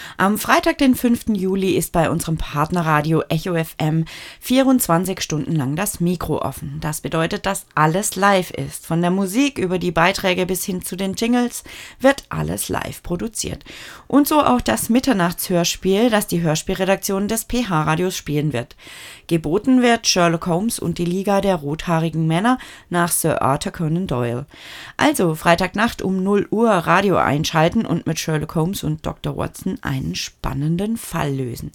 Kurzmitteilung – Live-Hörspiel
kurzmitteilung-live-hoerspiel.mp3